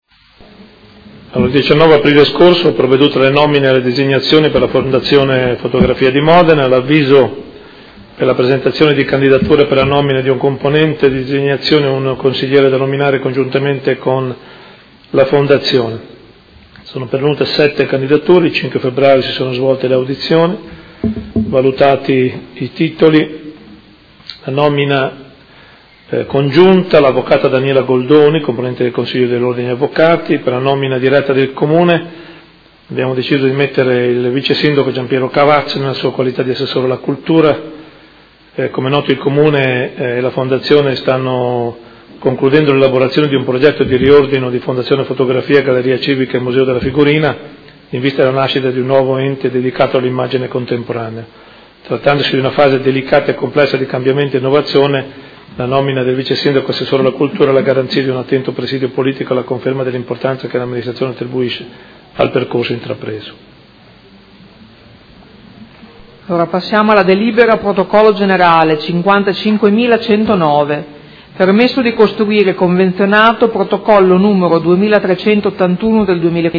Seduta del 28/04/2016. Comunicazione del Sindaco sulle nomine al cda della fondazione fotografia